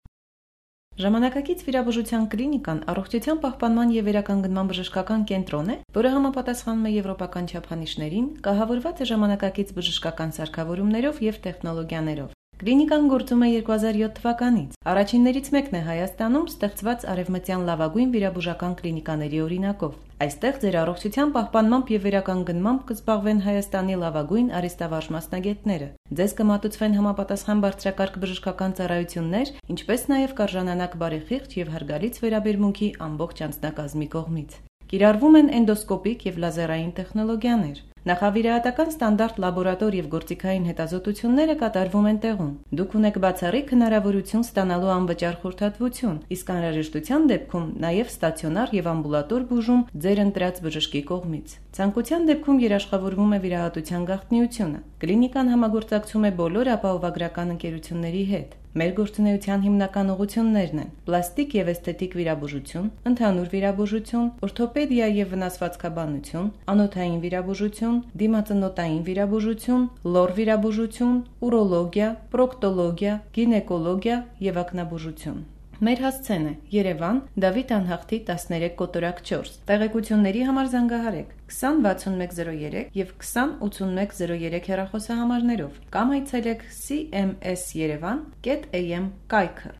Armenian fewmale voice
Sprechprobe: Werbung (Muttersprache):